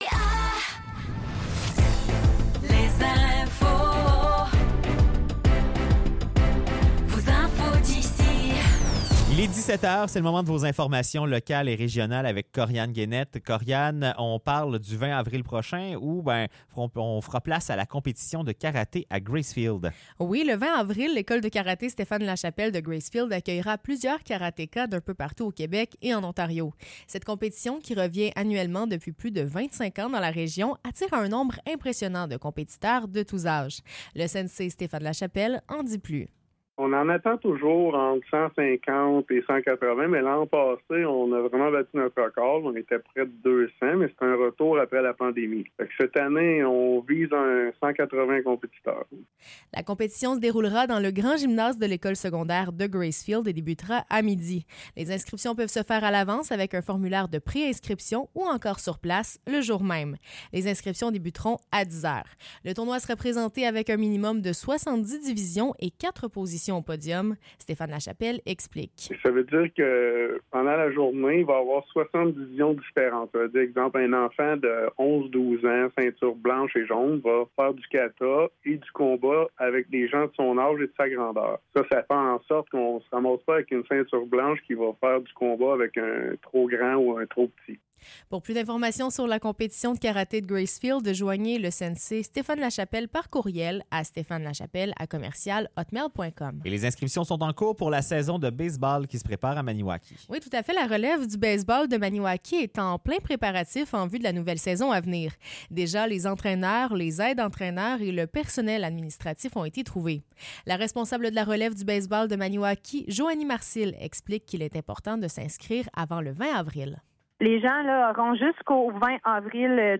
Nouvelles locales - 9 avril 2024 - 17 h